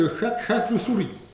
Langue Maraîchin
Patois
Catégorie Locution